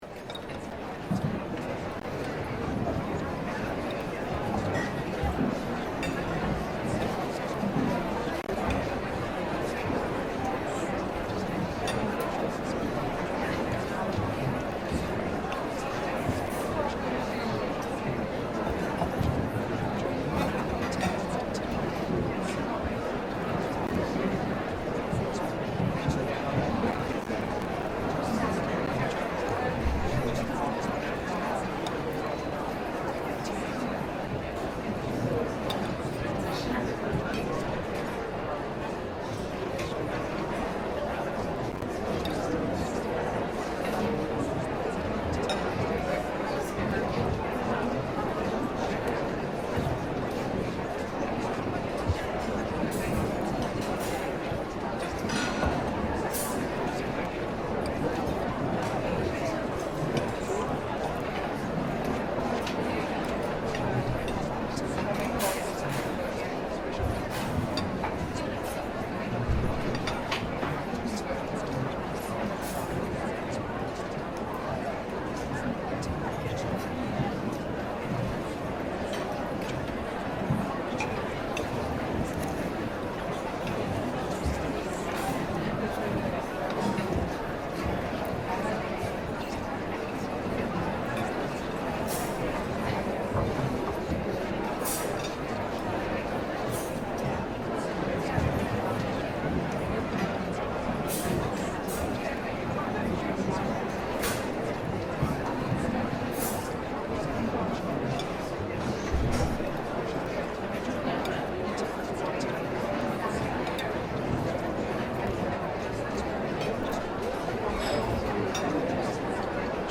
دانلود آهنگ جمعیت و مردم در کافه از افکت صوتی طبیعت و محیط
دانلود صدای جمعیت و مردم در کافه از ساعد نیوز با لینک مستقیم و کیفیت بالا
جلوه های صوتی